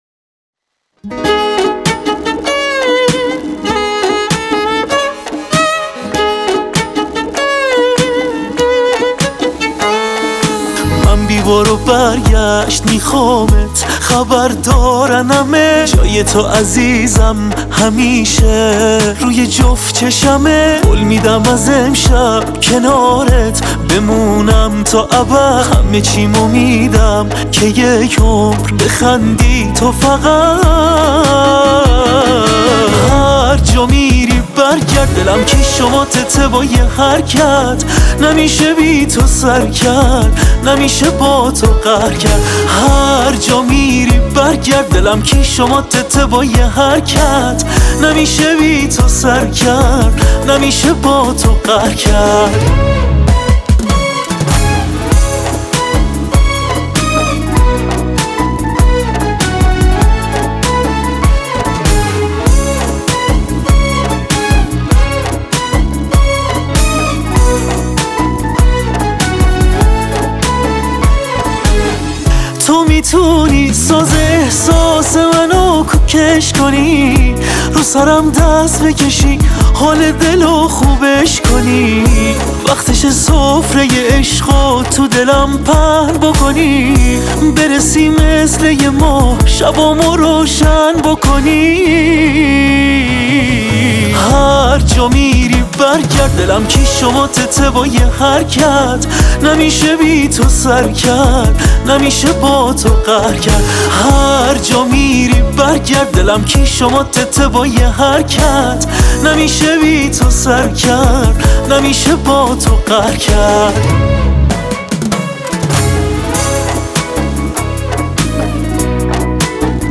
موزیک شاد